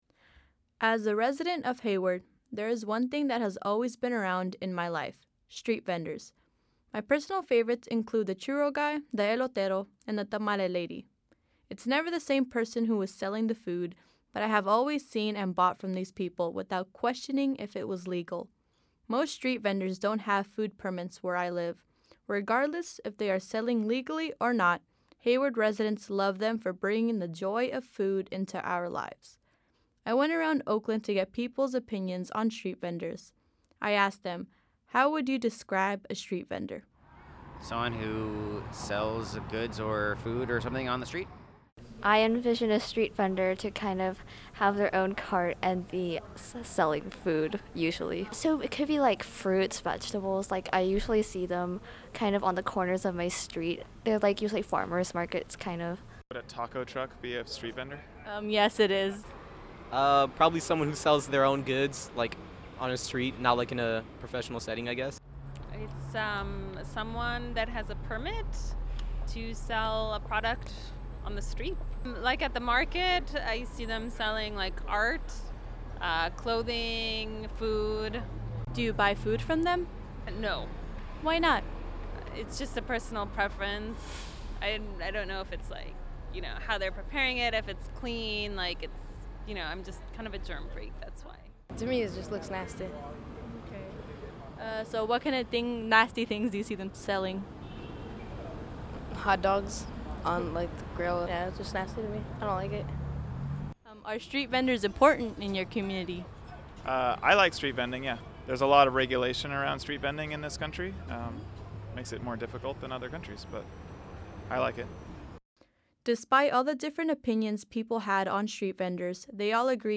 Some of my interviews are in Spanish so for those non-Spanish speakers here are the translations: